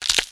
BREAK_Bright_stereo.wav